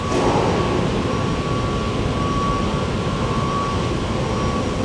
AMBIENT / INDUSTRIAL
WAREAMB2.WAV